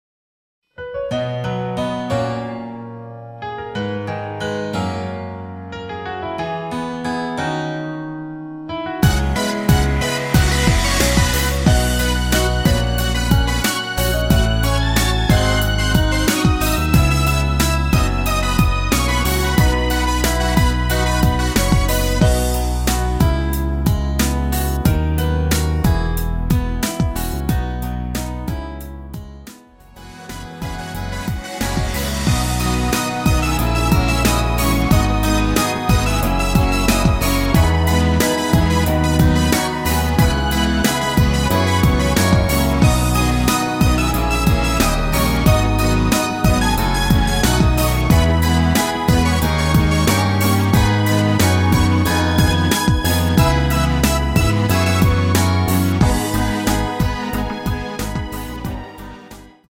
남자 혼자서 부르실수 있는 MR 입니다.